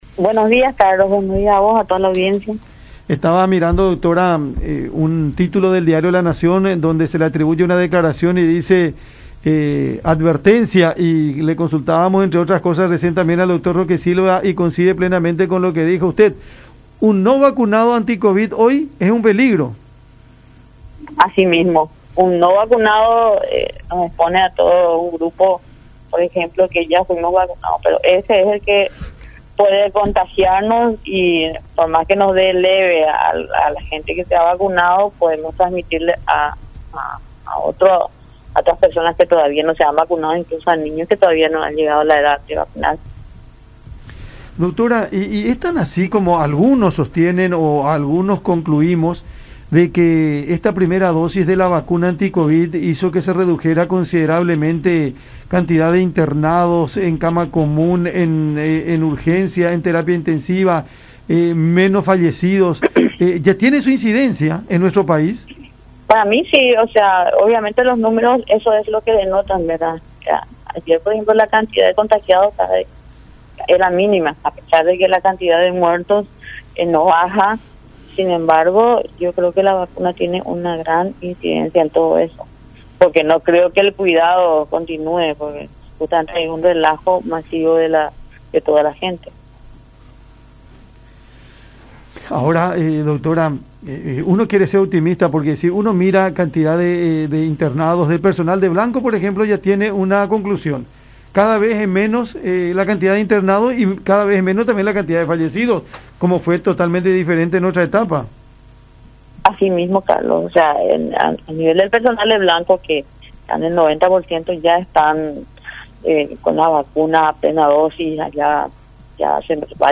en charla con Cada Mañana por La Unión